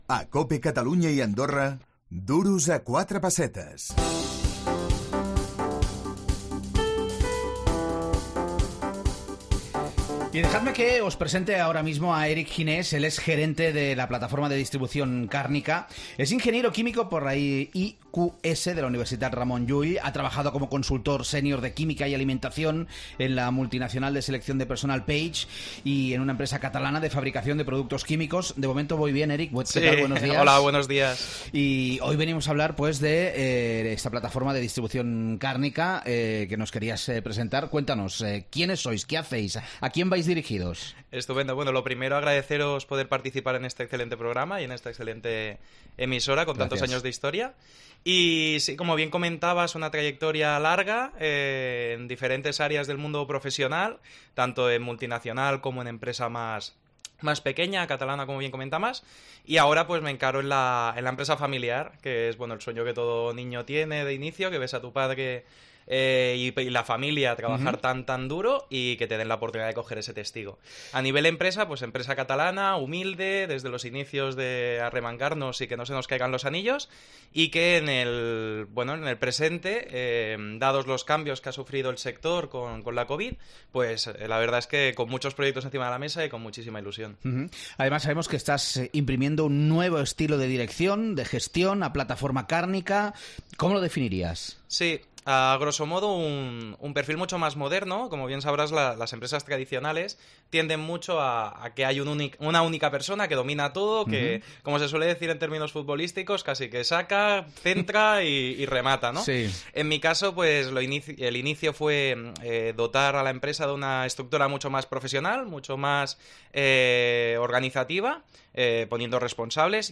L'actualitat econòmica, desde diferents angles, al “Duros a 4 pessetes”, el teu programa econòmic de capçalera. Analitzem totes aquelles notícies que poden afectar a la teva butxaca.